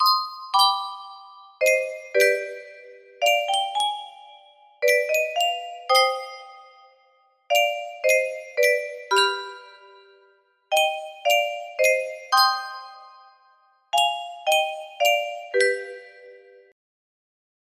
Yunsheng Music Box - Vivaldi The Four Seasons Summer 4028 music box melody
Full range 60